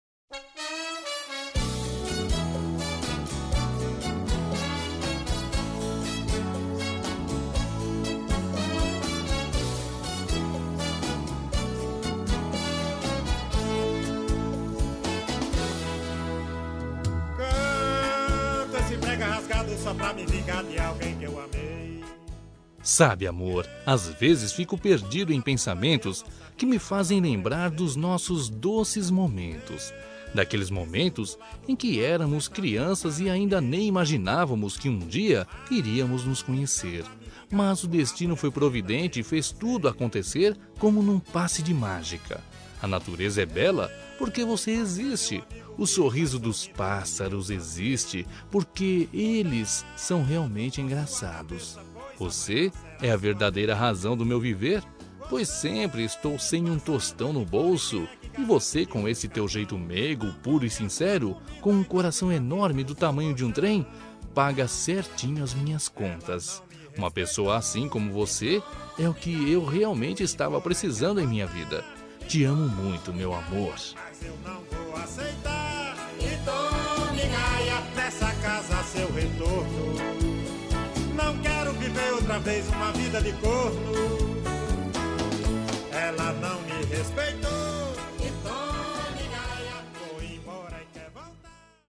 Aniversário de Humor – Voz Masculina – Cód: 200214